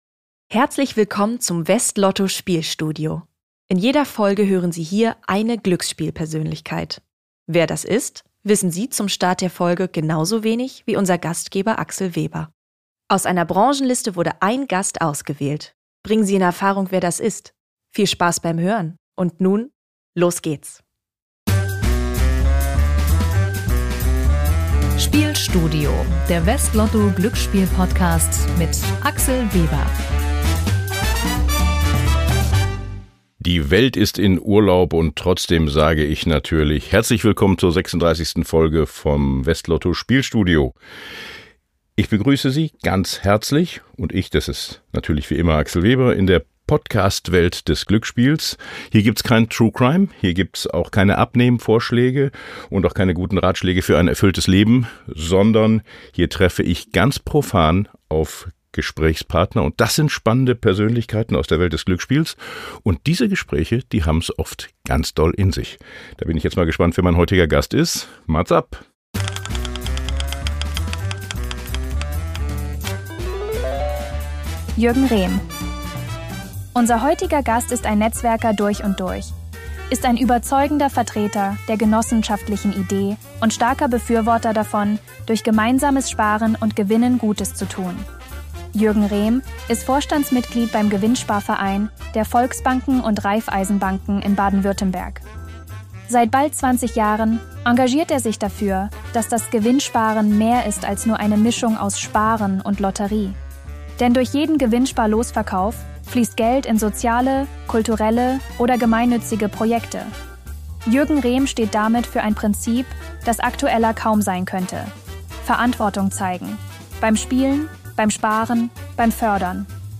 Hinweis: Diese Folge enthält einen Zuspieler, der von einer KI-Stimme gesprochen wird.